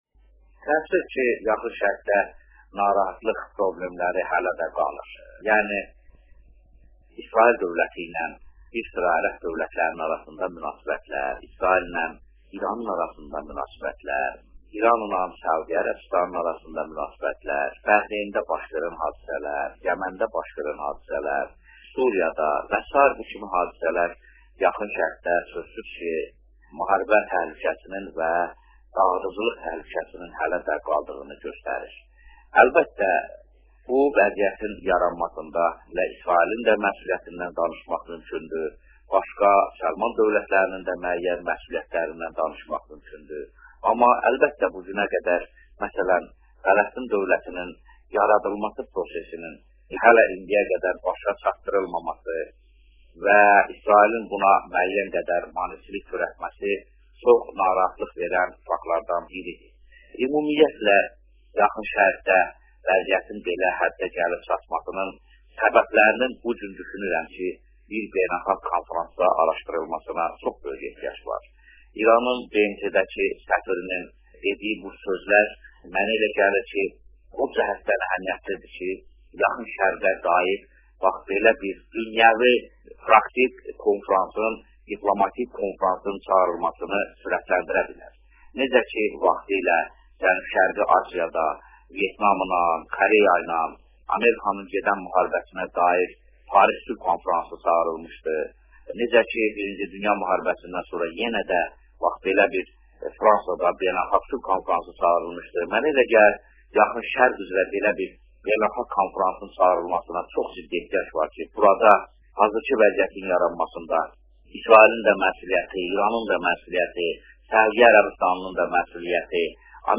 Azəri Radiosuna verdiyi eksklüziv müsahibədə